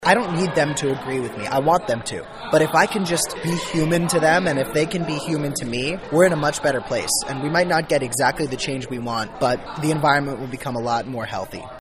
K-State was the latest destination for Kasky as he participated in a public question-and-answer presentation called “Tools for a Movement” at the K-State Student Union Tuesday.